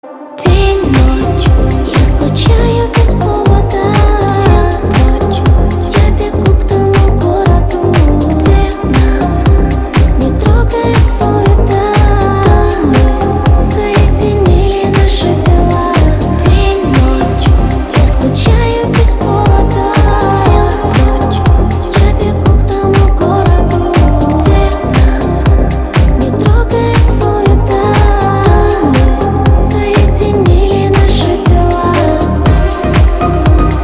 • Качество: 320, Stereo
женский вокал
deep house
Electronic
EDM
приятный женский голос
Приятный женский вокал